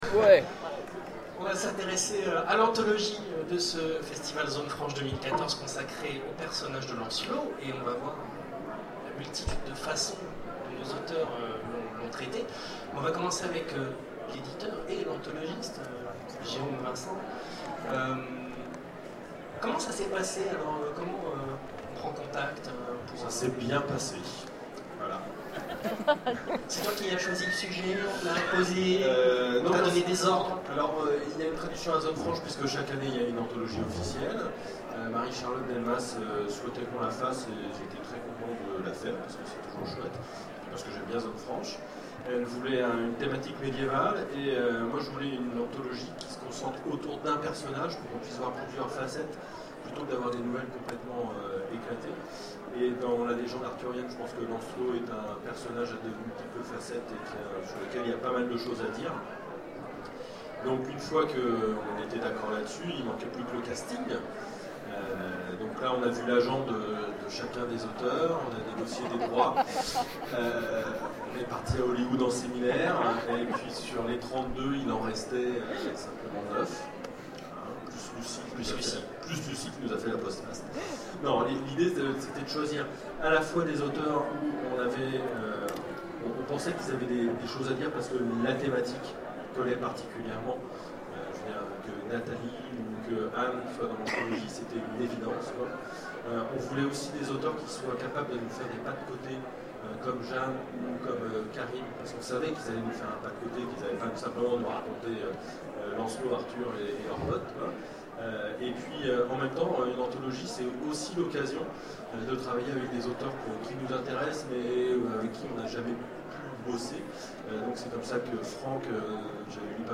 Zone Franche 2014 : Conférence Lancelot, l’anthologie 2014 du festival